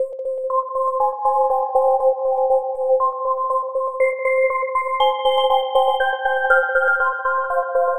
Night Chime 01.wav